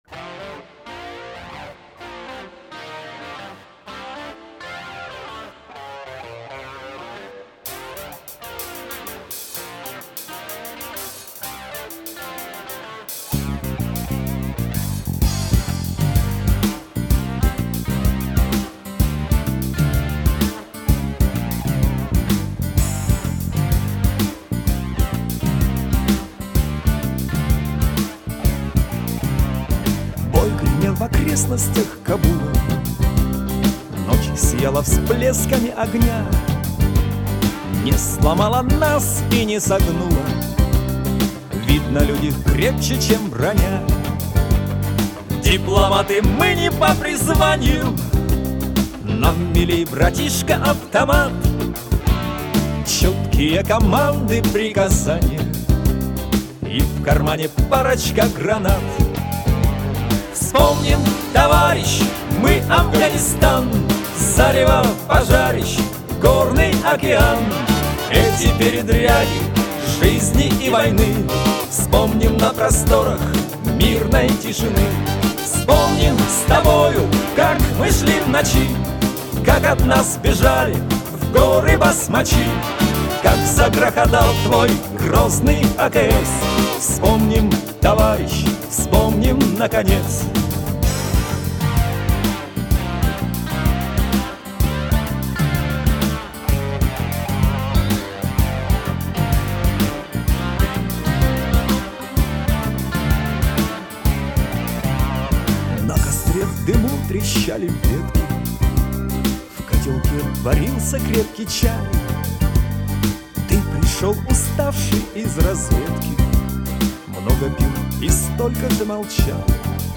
Главная » Файлы » Песни под гитару » Песни у костра
Песни у костра [44]